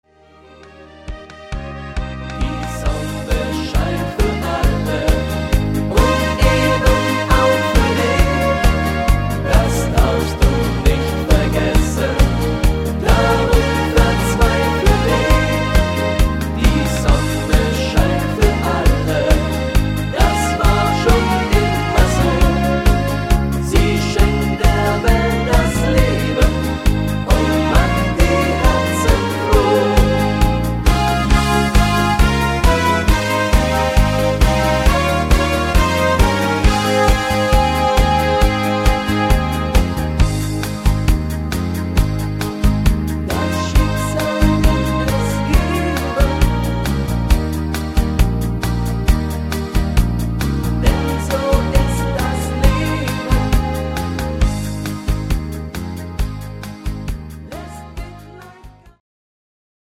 MP3 Playbacks17
Rhythmus  Party Marsch
Art  Deutsch, Volkstümlicher Schlager